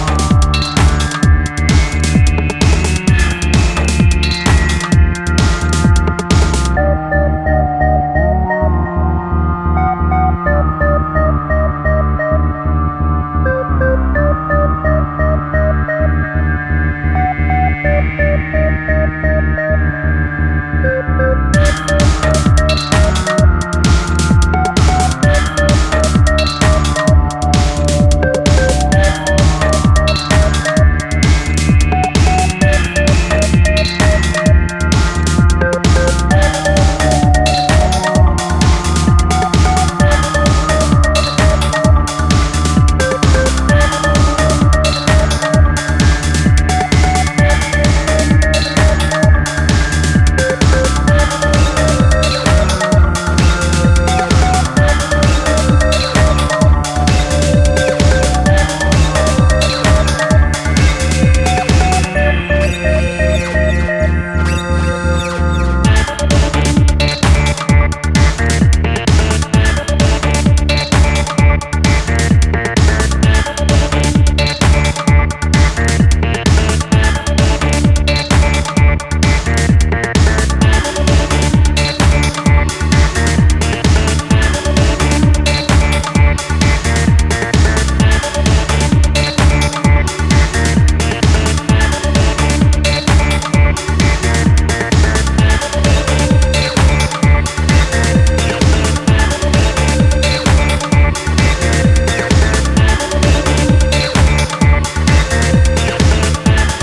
hypnotic & occult tunes
a gloomy & dark journey through techno, electro & acid.